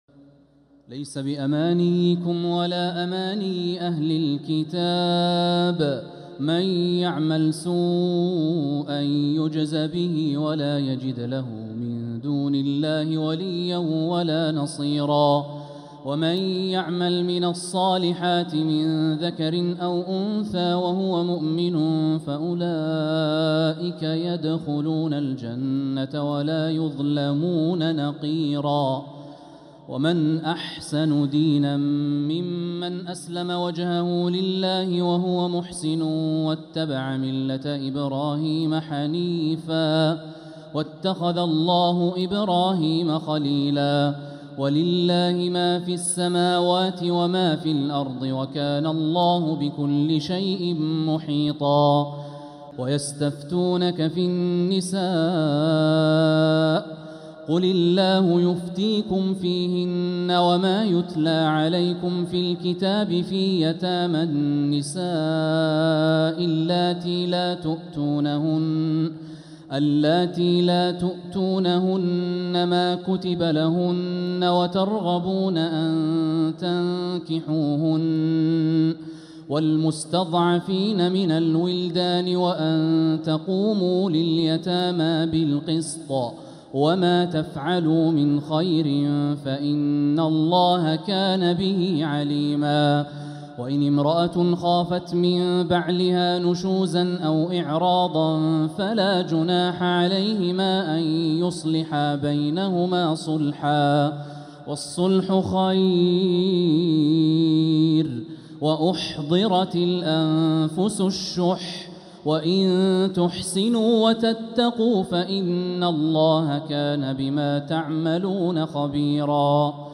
مقتطفات مرئية من محراب الحرم المكي من ليالي التراويح للشيخ د. الوليد الشمسان رمضان 1446هـ > تراويح الحرم المكي عام 1446 🕋 > التراويح - تلاوات الحرمين